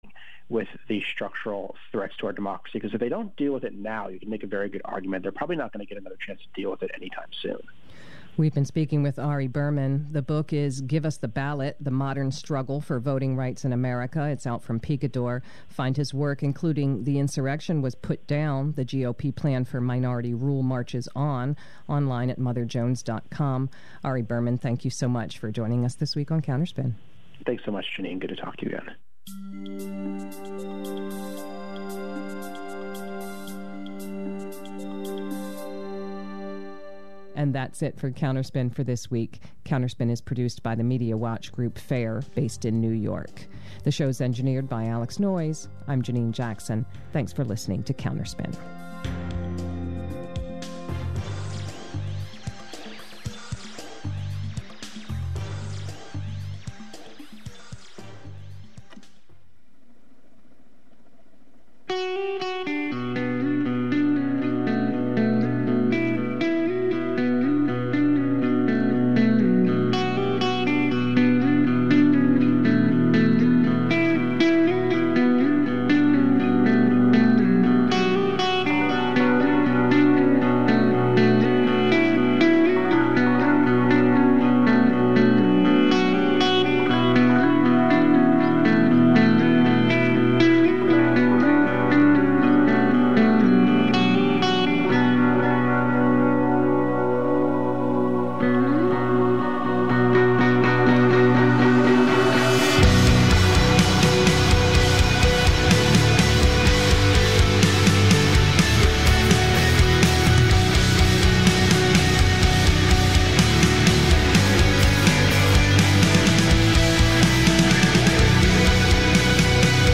Every 1st Monday from 7:00 pm to 8:00 pm Let’s Talk About Race (LTAR), a new intergenerational, roundtable discussion of independent national journalists featuring rigorous conversations and analysis of news coverage and the role race plays in politics, government, economy, education, and health. LTAR currently airs on KBOO Community Radio in Portland, OR .